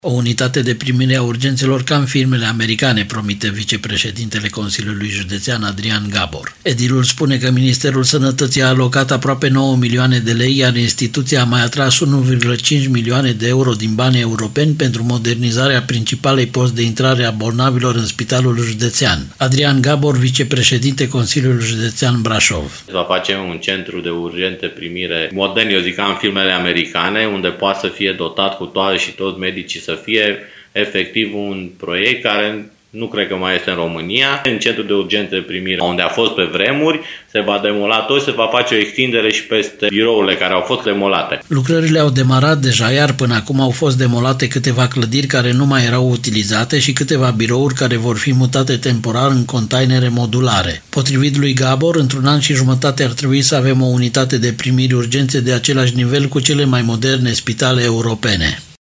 Vicepreşedintele Consiliul Judeţean Braşov, Adrian Gabor: